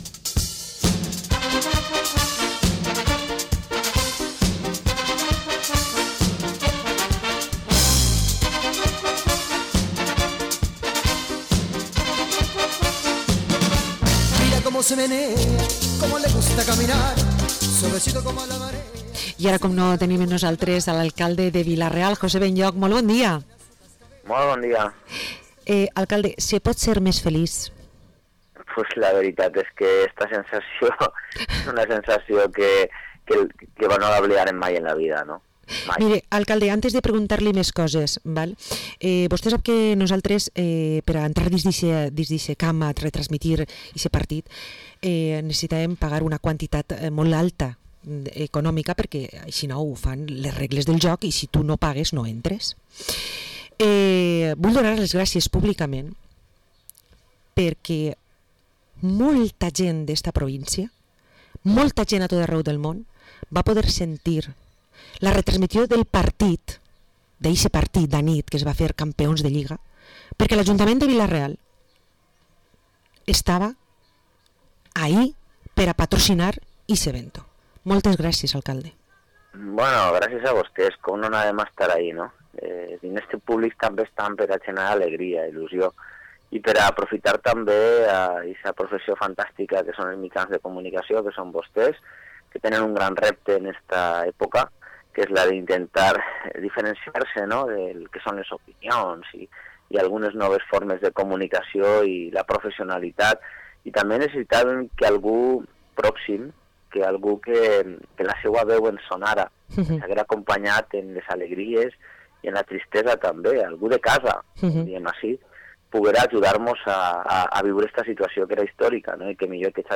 Entrevista al alcalde de Vila-real, José Benlloch – Radio Vila-real 92.2 FM